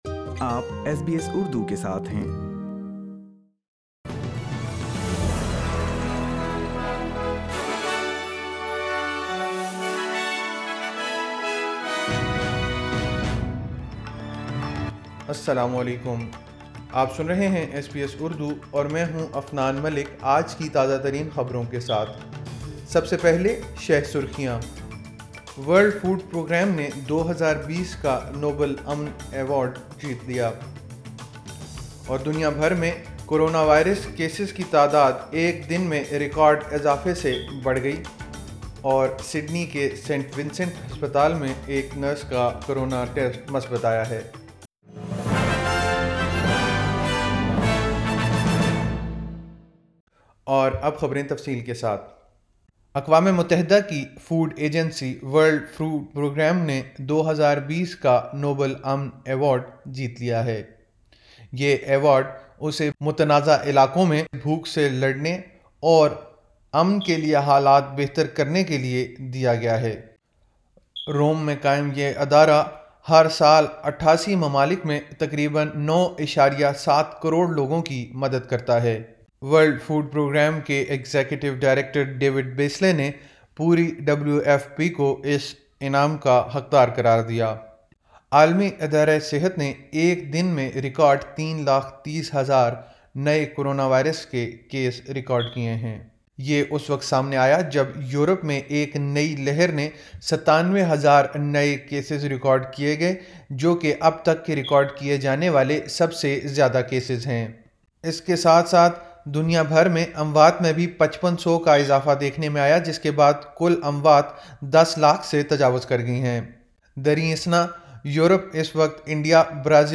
ایس بی ایس اردو خبریں 10 اکتوبر 2020